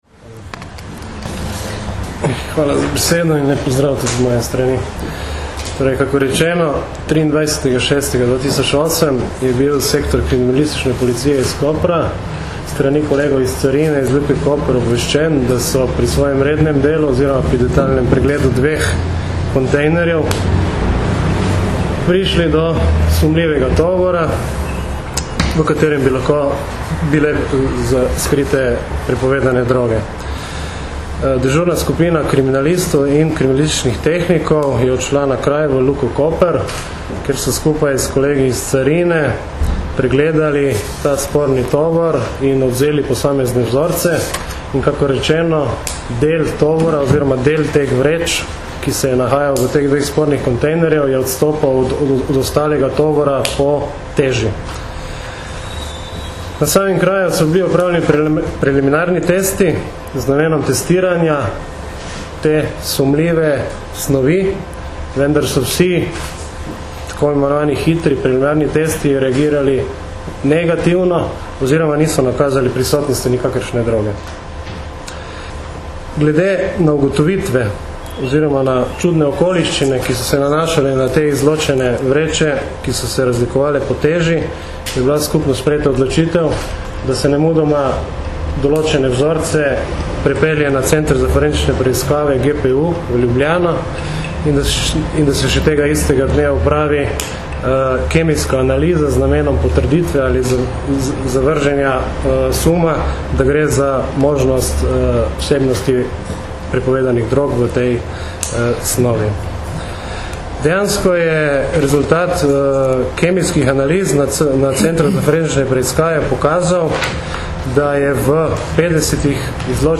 Predstavniki Generalne policijske uprave in Carinske uprave RS so na današnji novinarski konferenci predstavili odkritje večje količine prepovedane droge in zaključke kriminalistične preiskave organizirane mednarodne kriminalne združbe, v kateri je bilo prijetih več osumljencev.